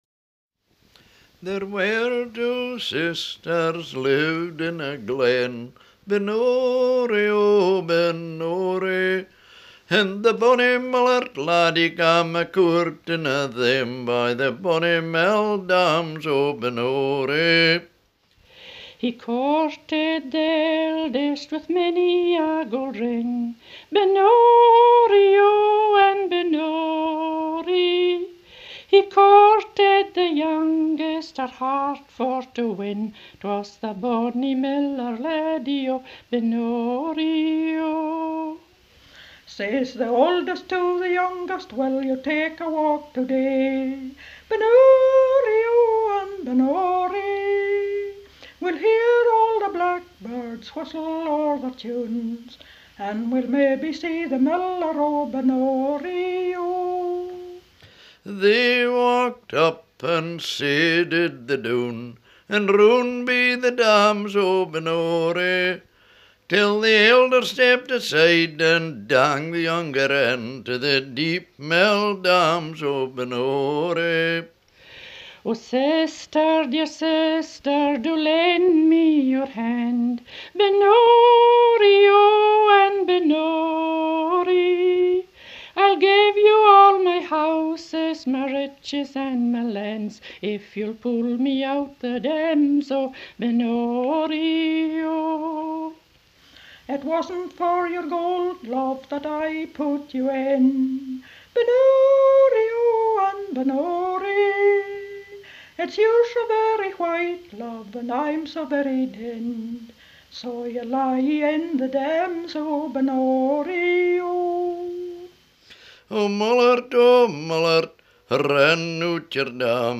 BALLATE DA INGHILTERRA, SCOZIA E IRLANDA